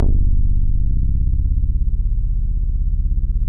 MOOG #8  C1.wav